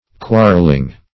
Quarreling \Quar"rel*ing\, a.